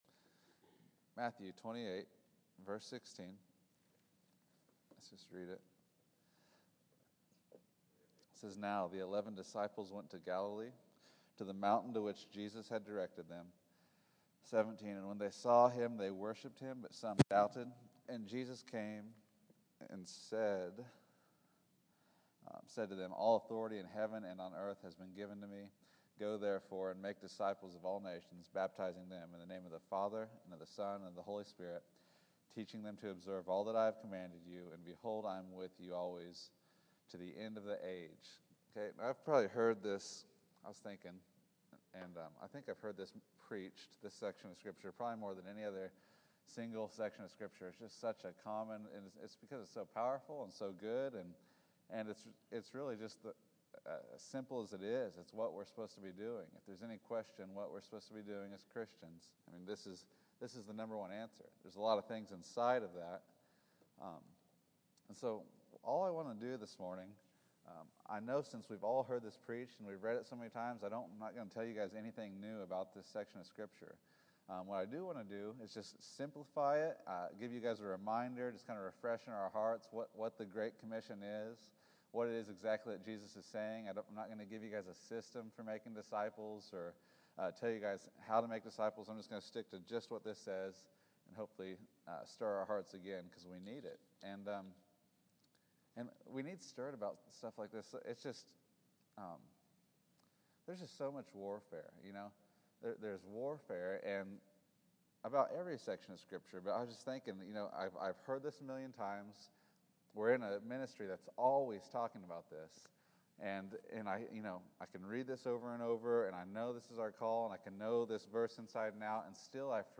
Matthew 28 April 27, 2014 Category: Sunday School | Location: El Dorado Back to the Resource Library The Great Commission.